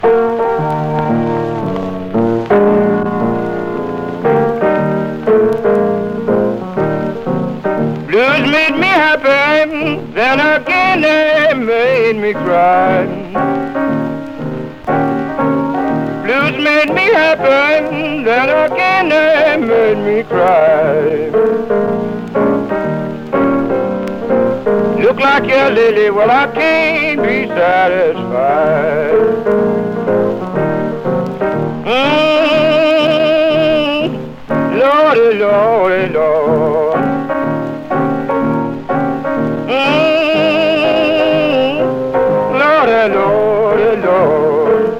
洒落っ気や都会的と形容されるブルースの音。
Blues　Netherlands　12inchレコード　33rpm　Stereo